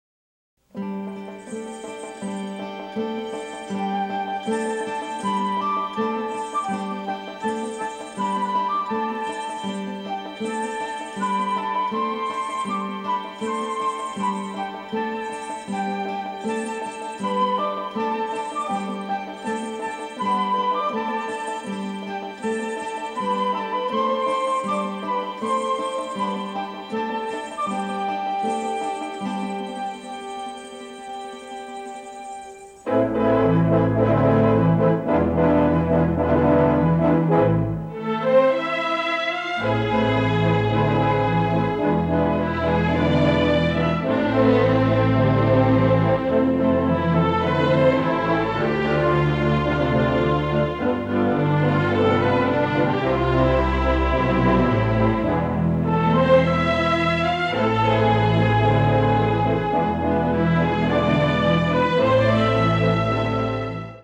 lush orchestral score